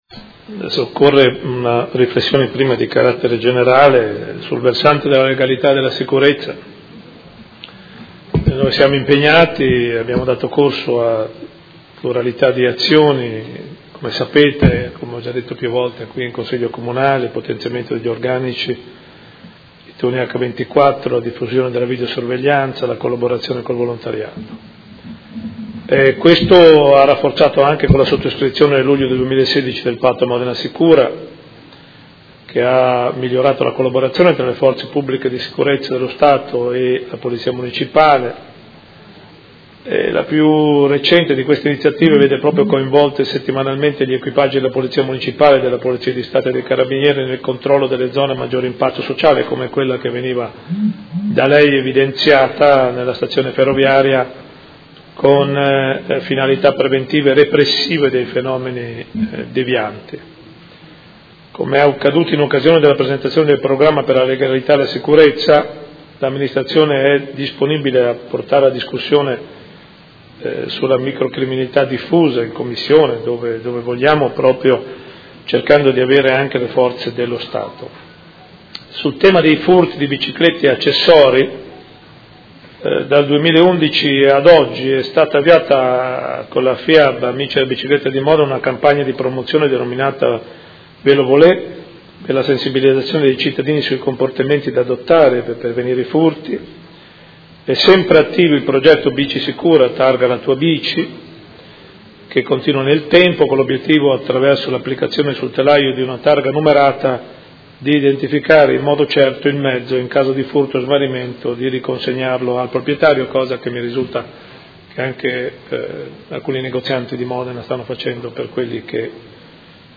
Seduta del 02/03/2017 Risponde. Interrogazione del Consigliere Morandi (FI) avente per oggetto: Furti di biciclette e accessori in varie zone della città, in particolare in Piazza Dante; quali provvedimenti intende adottare l’Amministrazione comunale?